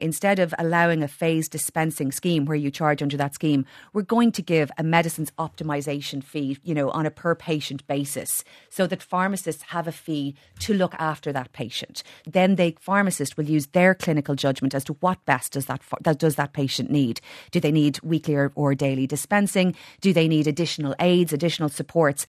Health Minister Jennifer Carroll MacNeill says this is a reformed approach…………